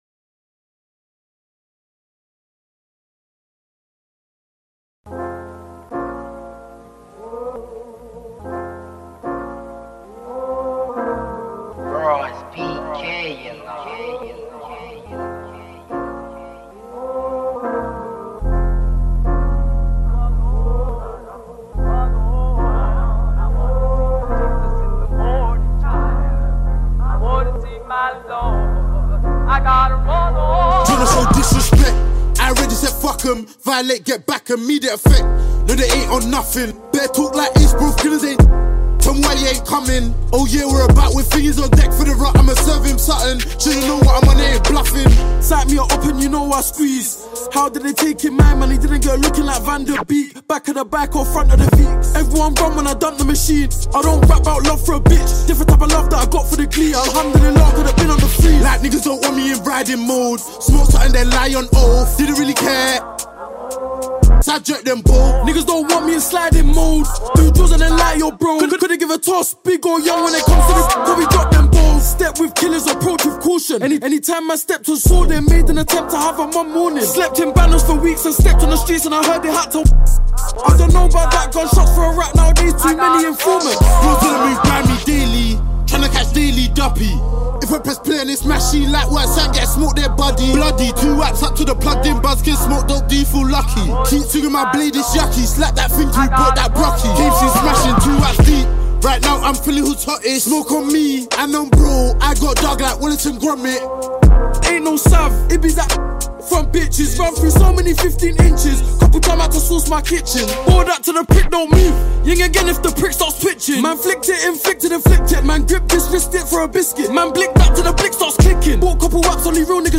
for me it's uk drill